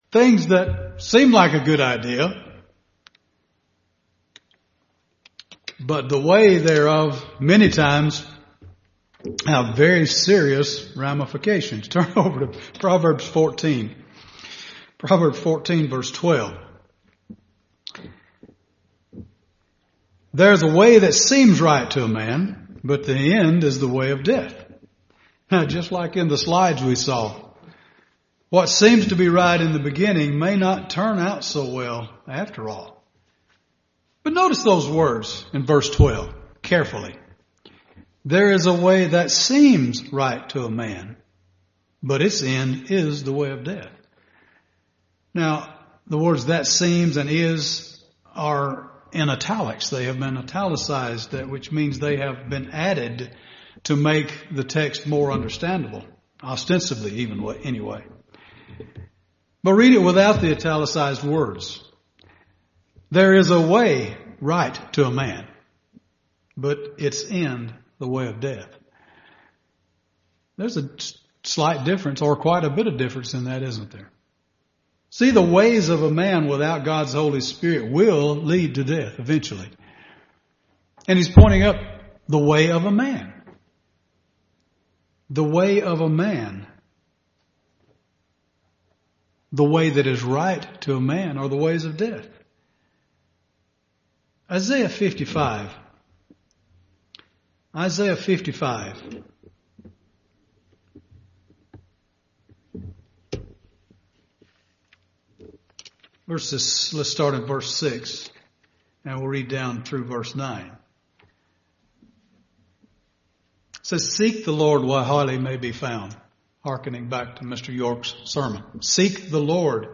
Given in Birmingham, AL Gadsden, AL
UCG Sermon Studying the bible?